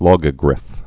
(lôgə-grĭf, lŏgə-)